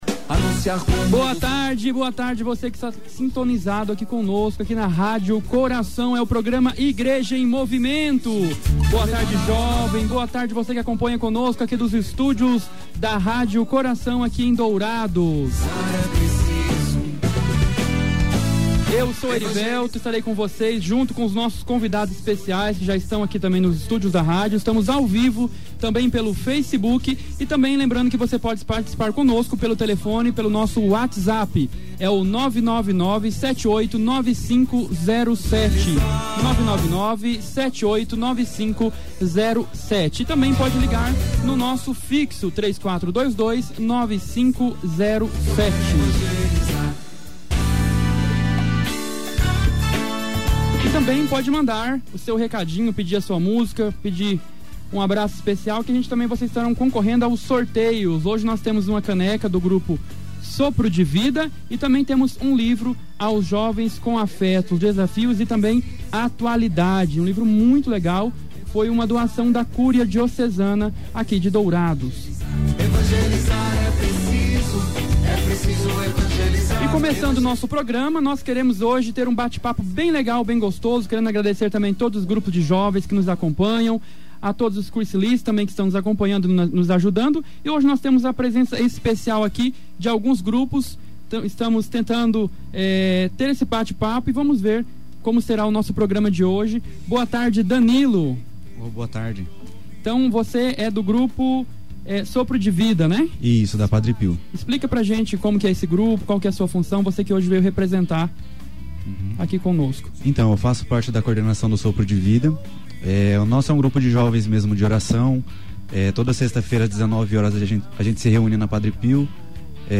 Participaram jovens representantes dos grupos 'Jovens da Milícia da Imaculada', 'Jovens Universitários Católicos', 'Grupo Samuel', 'Grupo Sopro de Vida', além dos próprios membros do Cursilho de Jovens.
Na tarde de sábado (14/04), o programa 'A Igreja em Movimento' com o Cursilho de Jovens, foi um bate papo com alguns grupos de jovens de Dourados.